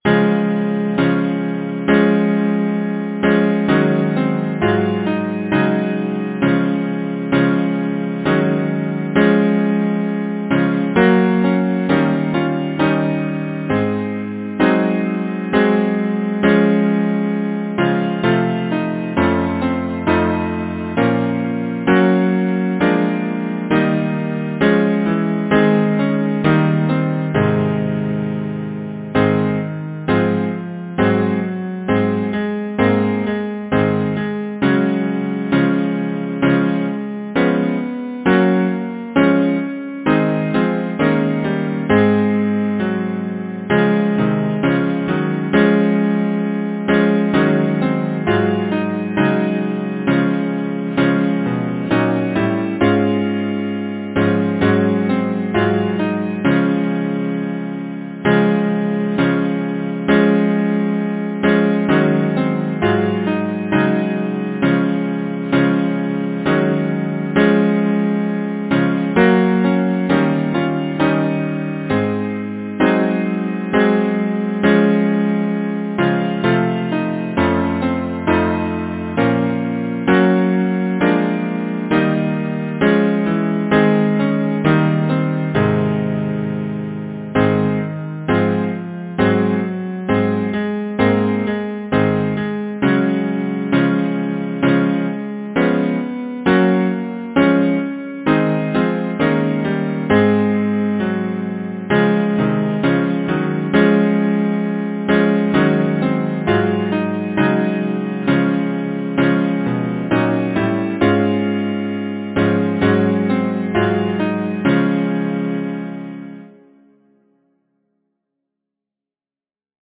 Title: Blissful dreams Composer: William H Pontius Lyricist: Number of voices: 4vv Voicing: TTBB Genre: Secular, Partsong
Language: English Instruments: A cappella